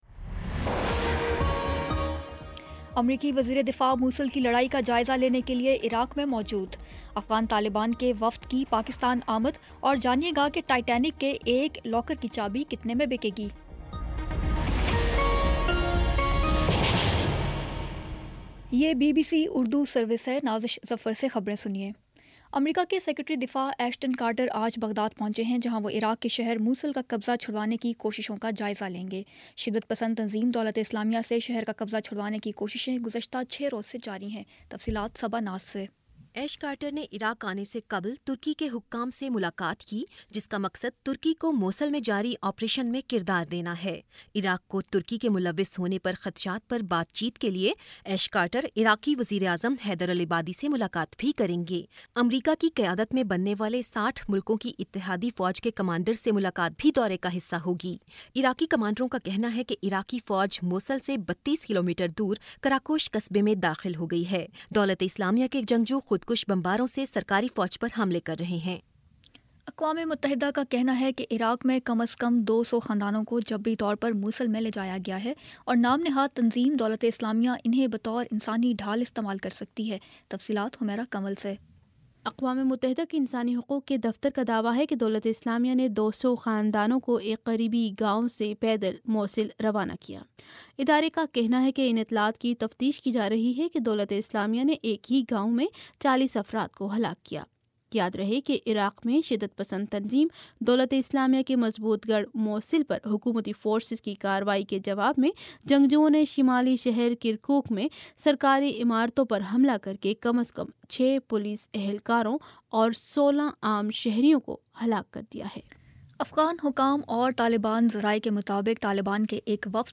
اکتوبر22 : شام سات بجے کا نیوز بُلیٹن